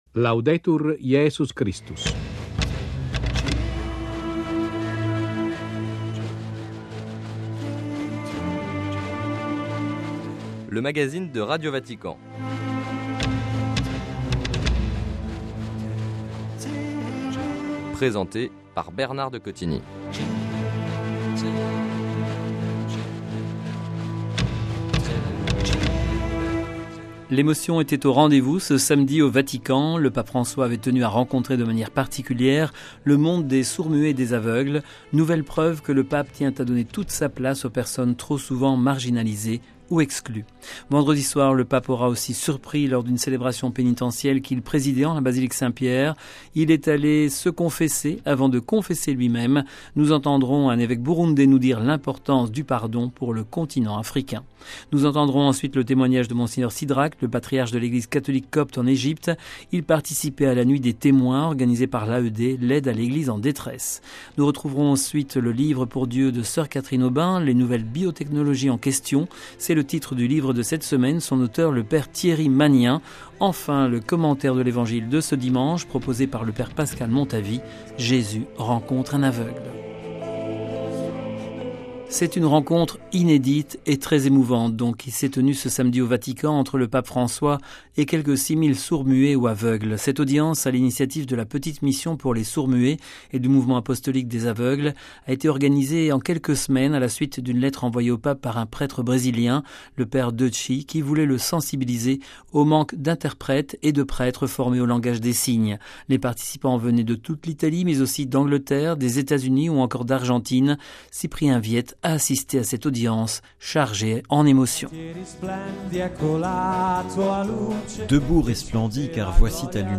Nous entendrons un évêque burundais nous dire l’importance du pardon pour le continent africain.